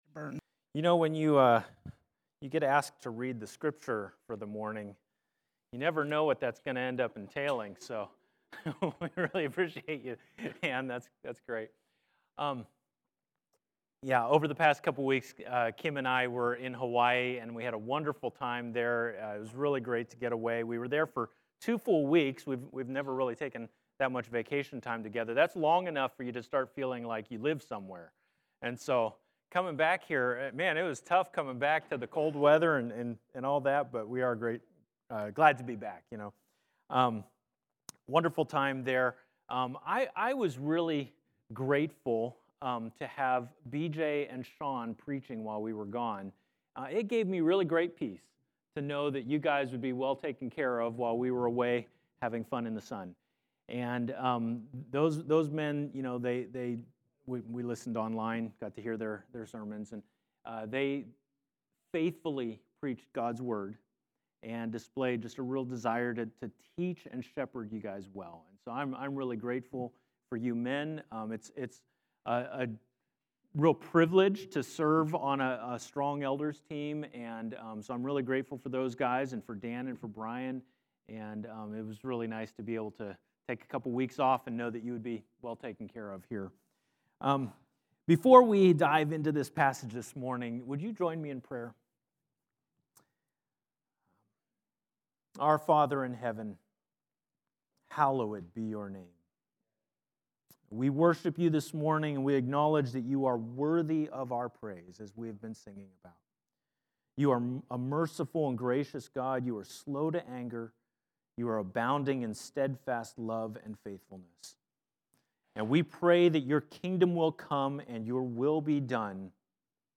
Sermons | Grace Church - Pasco